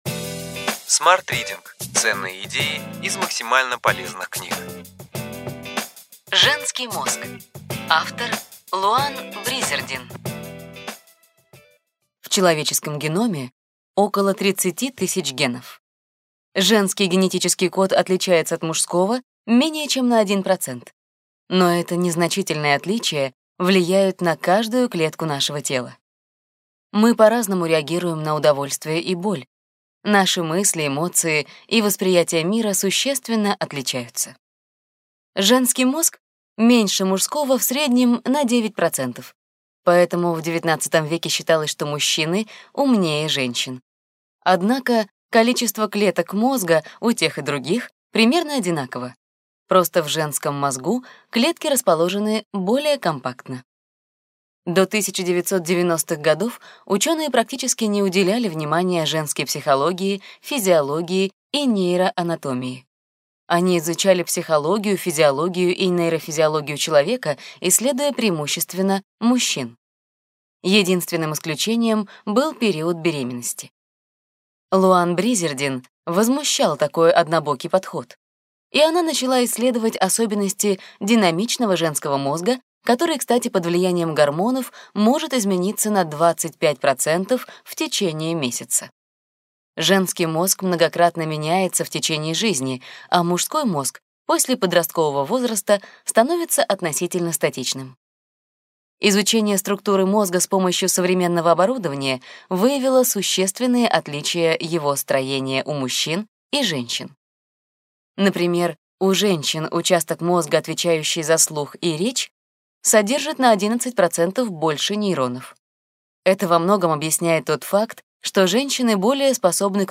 Аудиокнига Ключевые идеи книги: Женский мозг. Луанн Бризердин | Библиотека аудиокниг